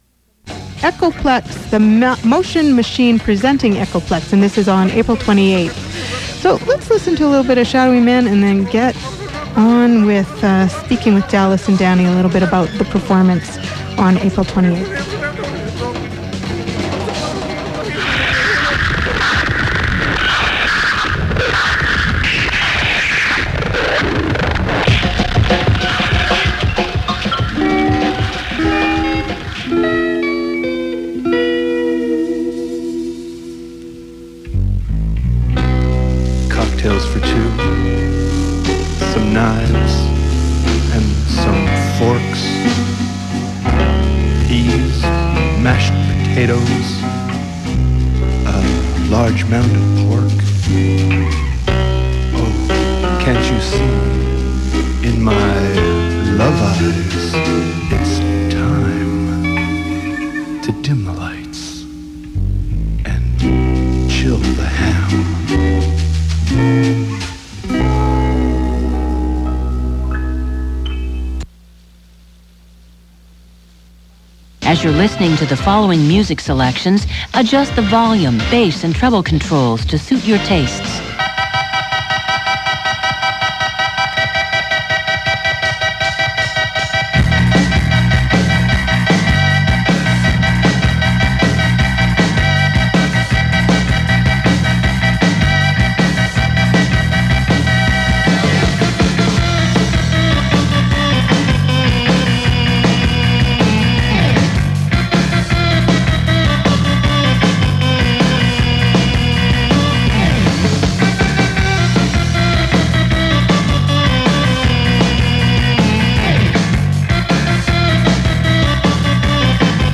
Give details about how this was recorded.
cjsw-echoplex-01.mp3